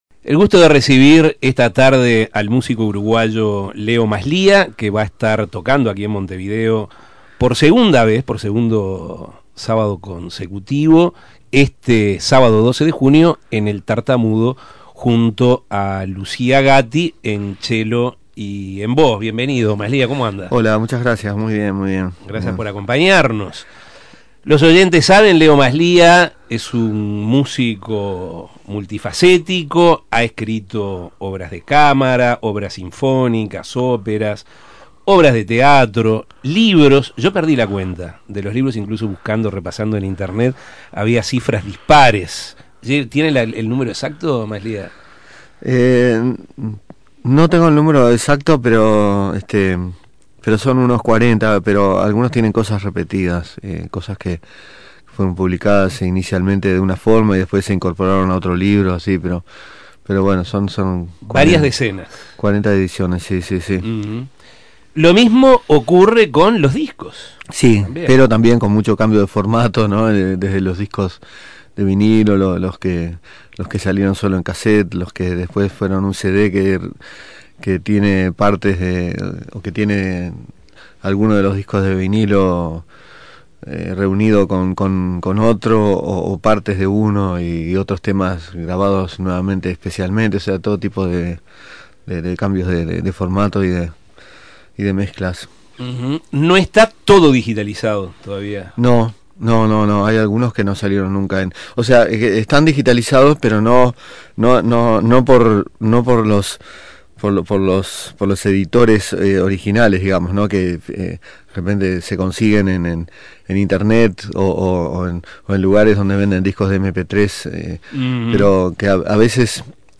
Entrevistas Masliah vuelve a las tablas Imprimir A- A A+ Leo Masliah no tiene Facebook, MySpace ni blog, pero tiene de su autoría obras de cámara, obras sinfónicas, óperas, obras de teatro y libros por decenas.